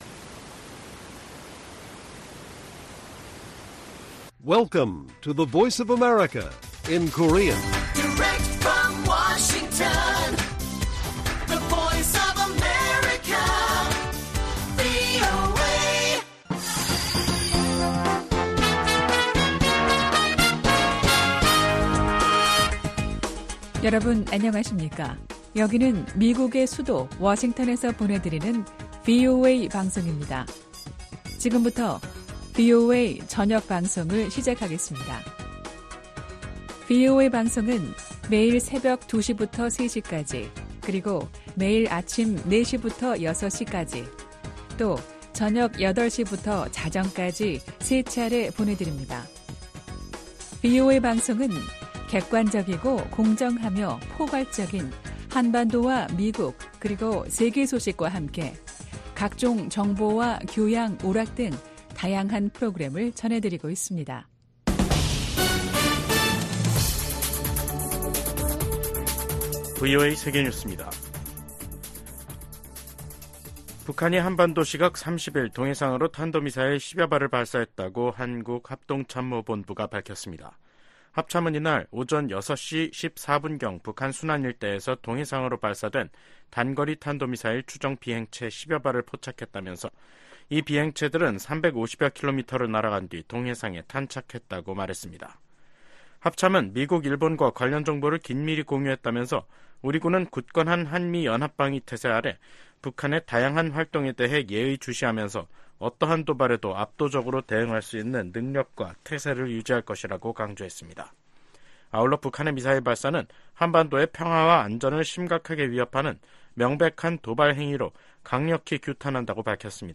VOA 한국어 간판 뉴스 프로그램 '뉴스 투데이', 2024년 5월 30일 1부 방송입니다. 북한이 30일, 동해상으로 단거리 탄도미사일 10여발을 발사했습니다.